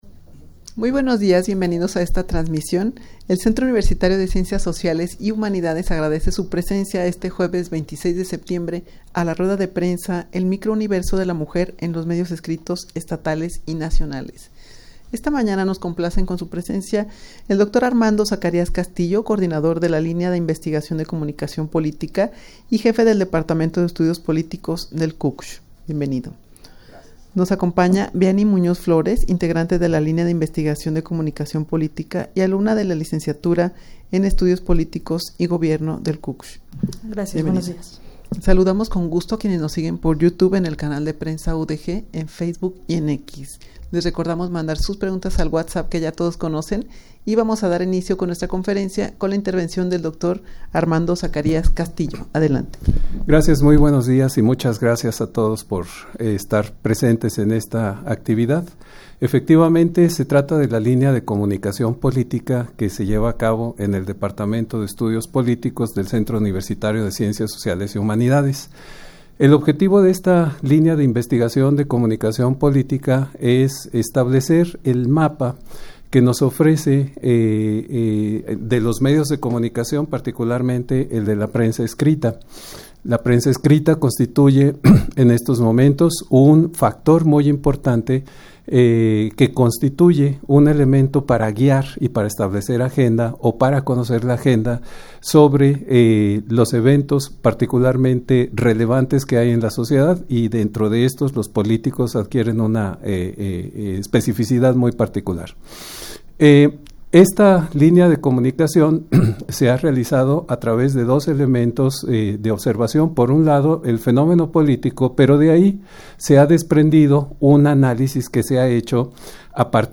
Audio de la Rueda de Prensa
rueda-de-prensa-el-microuniverso-de-la-mujer-en-los-medios-escritos-estatales-y-nacionales.mp3